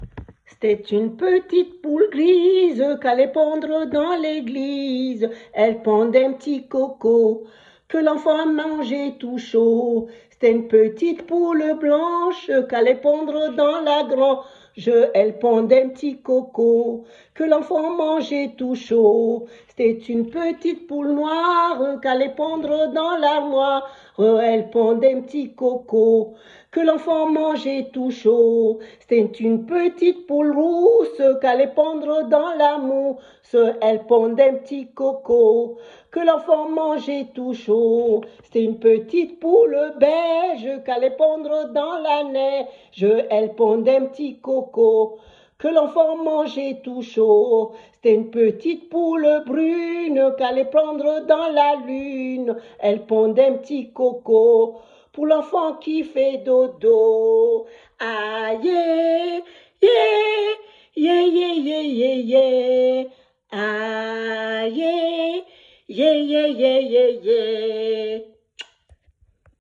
L’enfant peut écouter comme berceuse ou en court récit en chanson 🎶 pour apaiser les pleures pendant les repas ou le bain…